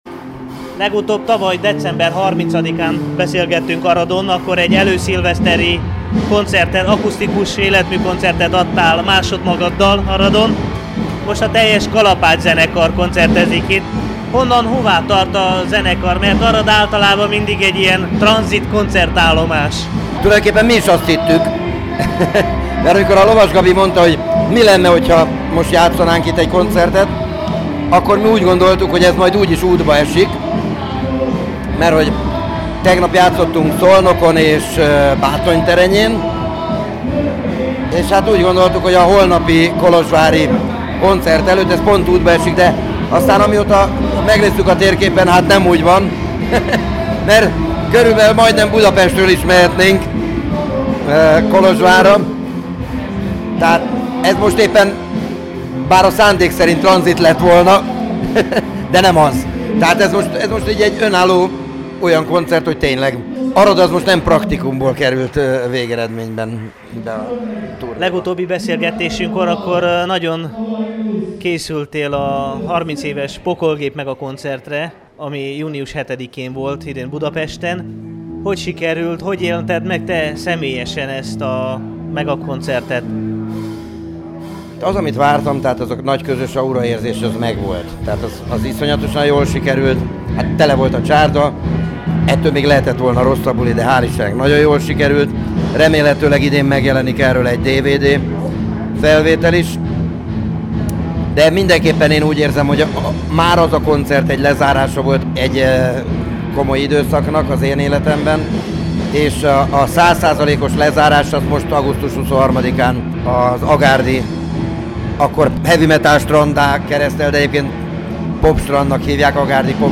Az interjú a Temesvári Rádió mai ifjúsági műsorában hangzott el.